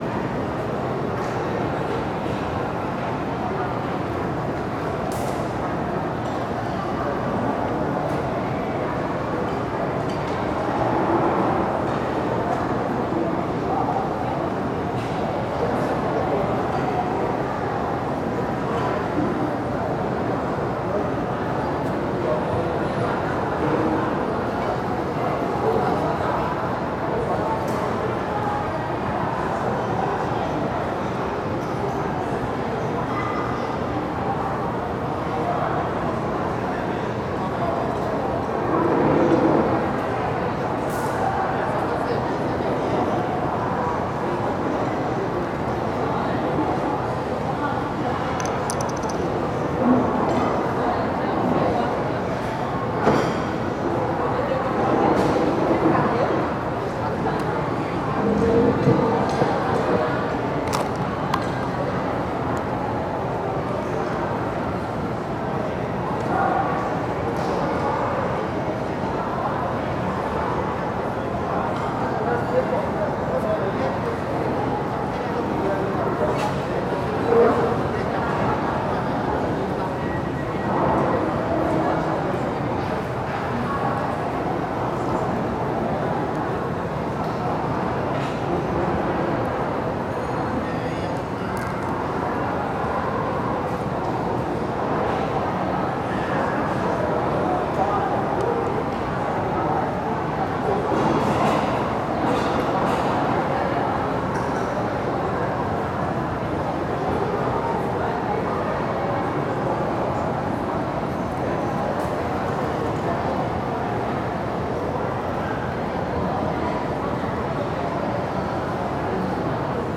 Almoco em casa festa lago norte com pessoas, cachorro, criancas brincando
Vozerio Brasília , Lago Norte Surround 5.1